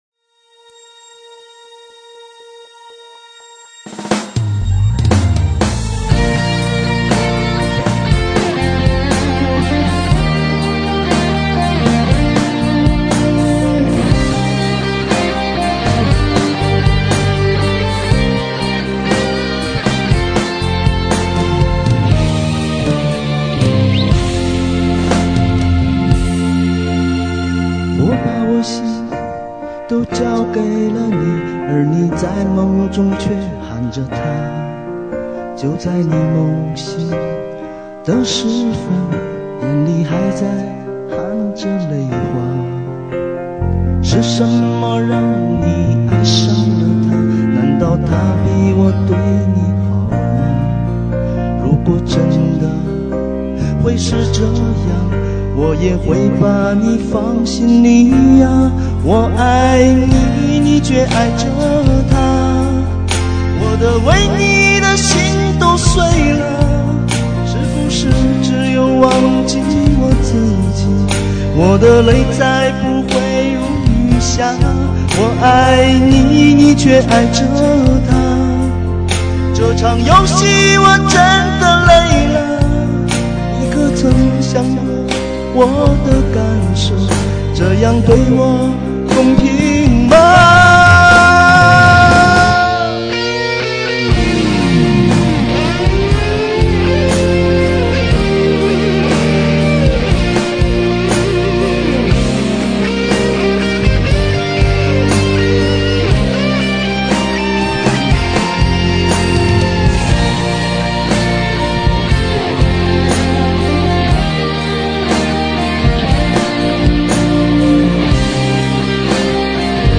没啥录音设备，纯自娱自乐。
效果不咋好，有缘听到的大可一笑了之。。。